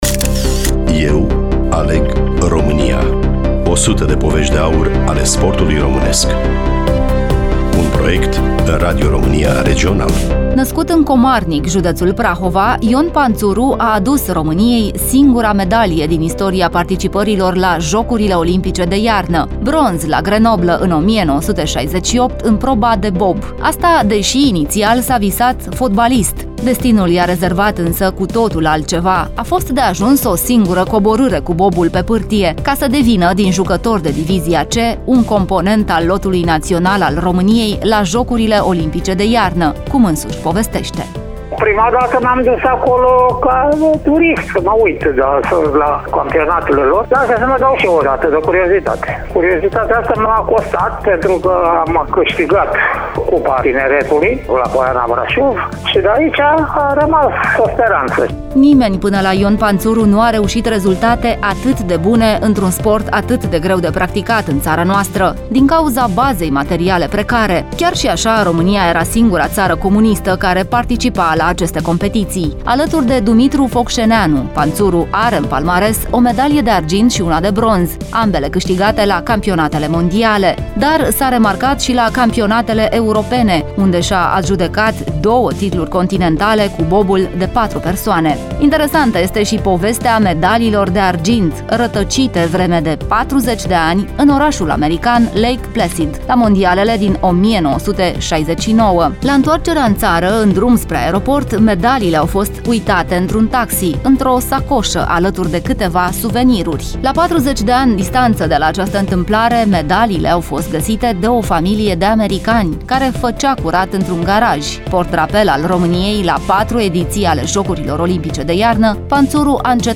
Ion-Panturu-Radio.mp3